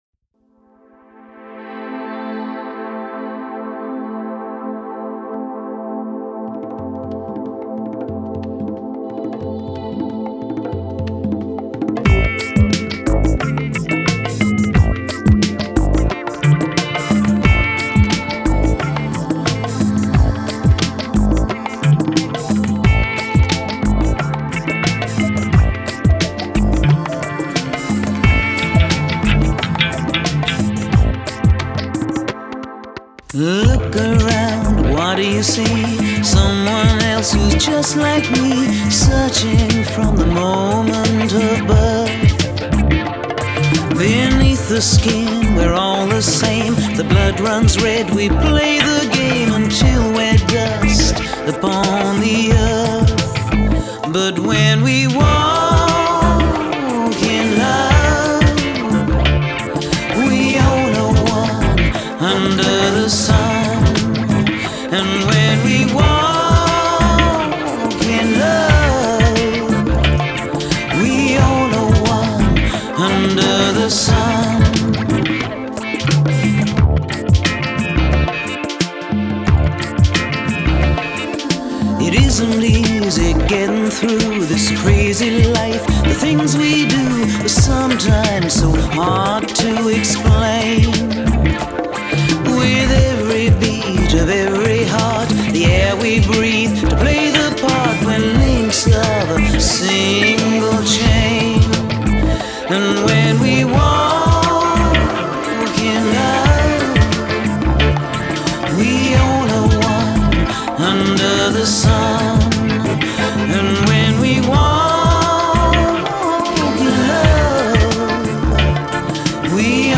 Жанр: Spiritual